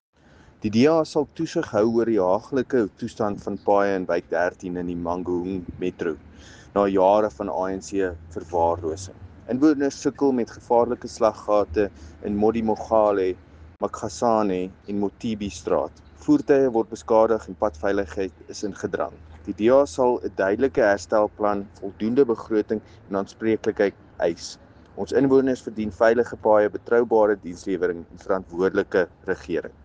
Afrikaans soundbite by Cllr Paul Kotze and